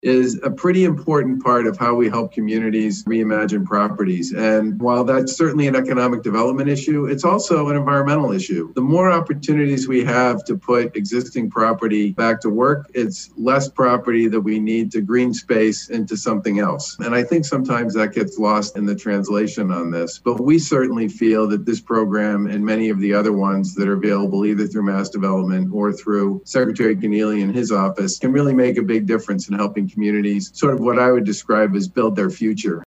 Governor Charlie Baker said that programs like this provide both an economic and environmental benefit.